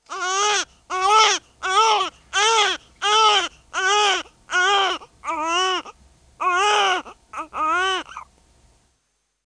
Tiếng em bé Sơ sinh Khóc
Download tiếng em bé sơ sinh khóc mp3, tải hiệu ứng tiếng em bé sơ sinh khóc không yêu cầu bản quyền, tải miễn phí âm thanh trẻ sơ sinh khóc chất lượng...